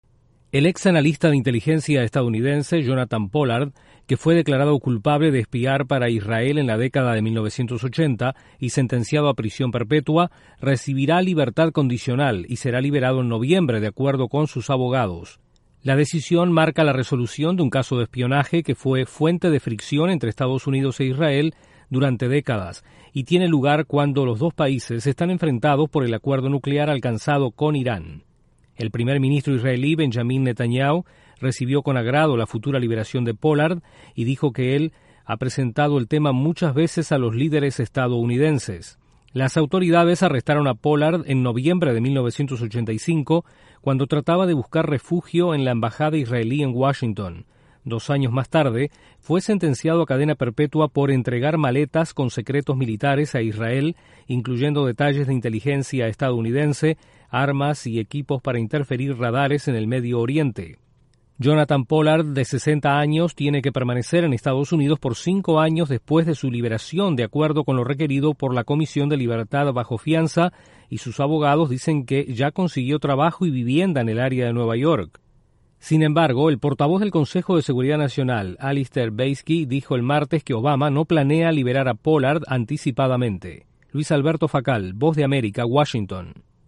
EE.UU. liberará al espía israelí Jonathan Pollard tras 30 años en prisión. Desde la Voz de América en Washington